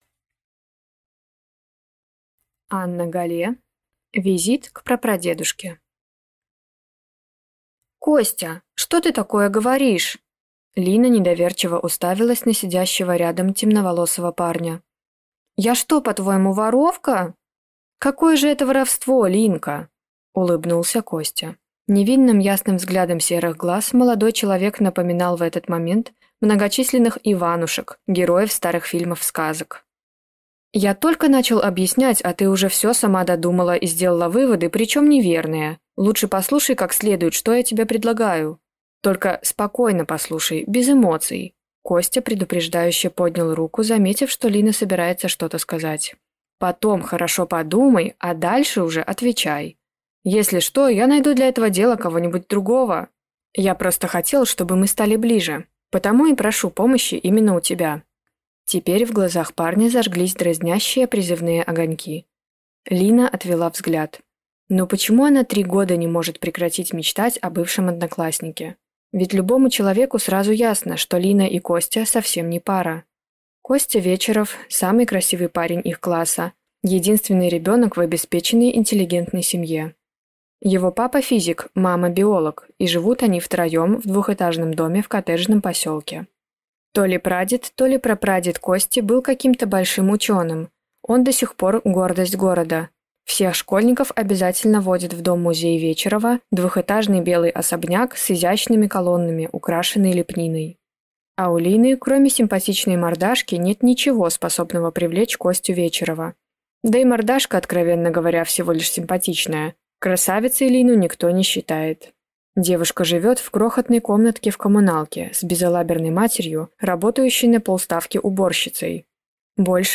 Аудиокнига Визит к прапрадедушке | Библиотека аудиокниг
Прослушать и бесплатно скачать фрагмент аудиокниги